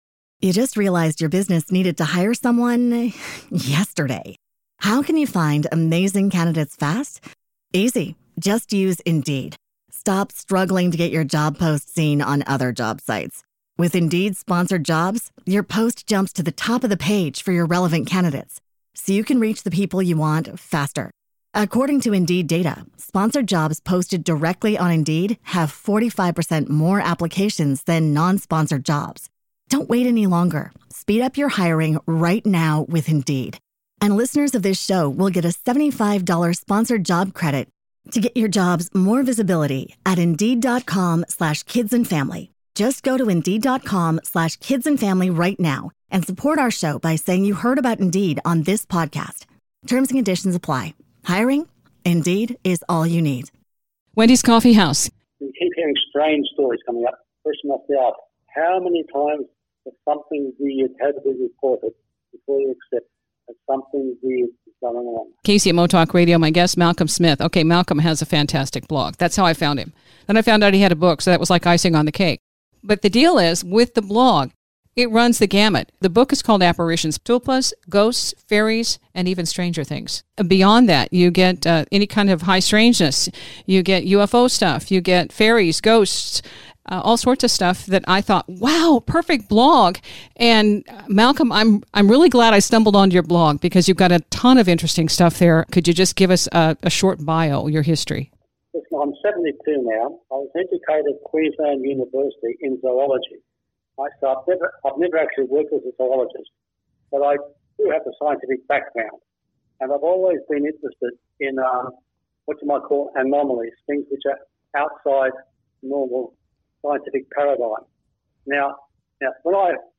We focus on the book and the blog in our interview